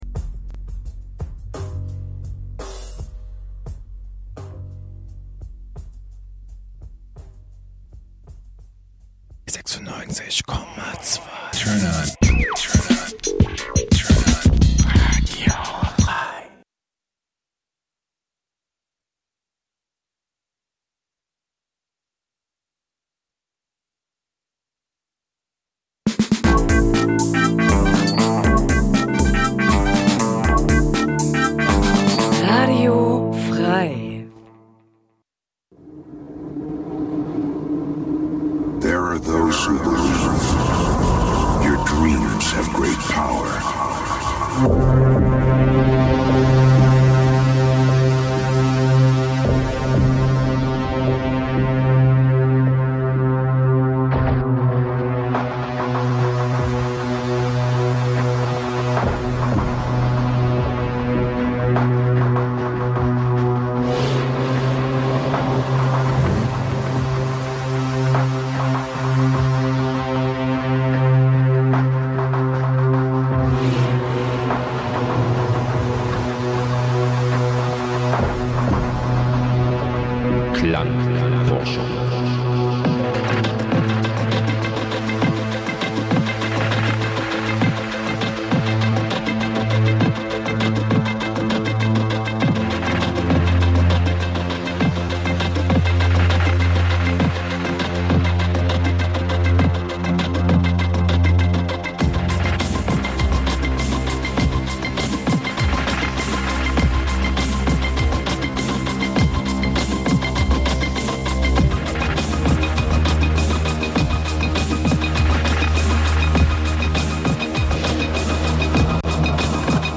Abstrakte Soundwelten, nie geh�rte Stimmen und musikgewordene Filme f�r Dein Hirnkino, jenseits von Eurodance und Gitarrengeschrammel.
Das Spektrum der musikalischen Bandbreite reicht von EBM , Minimalelektronik, Wave ,Underground 80`s bis hin zu Electro ,Goth und Industriell.
Sendung für elektronische Musik Dein Browser kann kein HTML5-Audio.